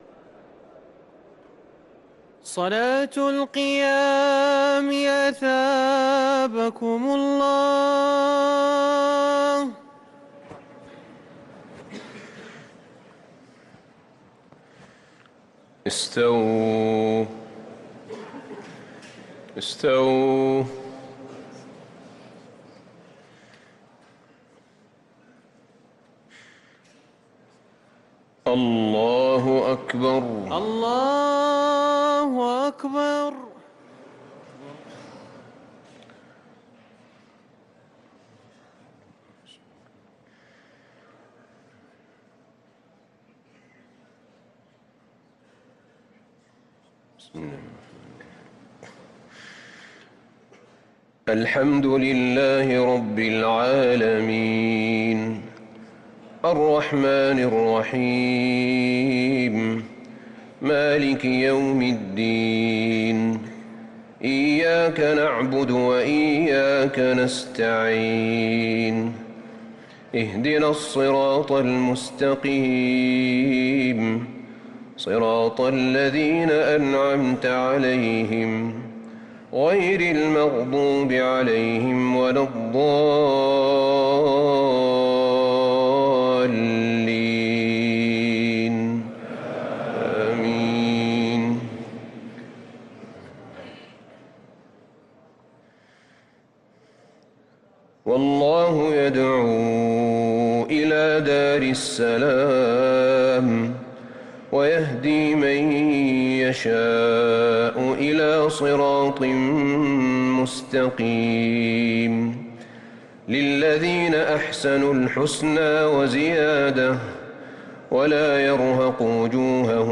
صلاة التراويح ليلة 15 رمضان 1444 للقارئ أحمد بن طالب حميد - الثلاث التسليمات الأولى صلاة التراويح